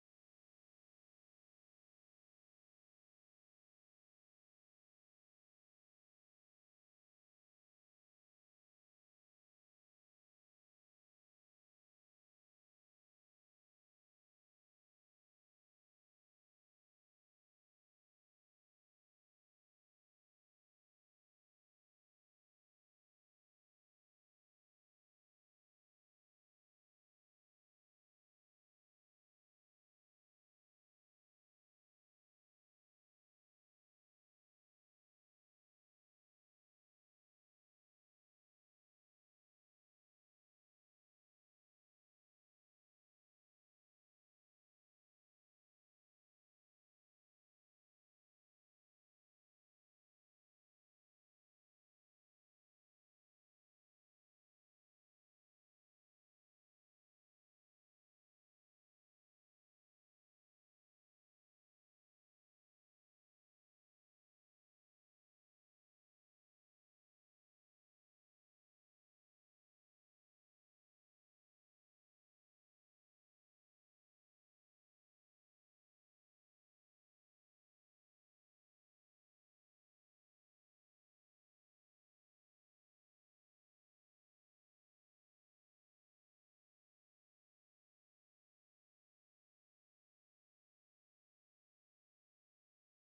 Скачать музыку / Музон / Фонк (Phonk)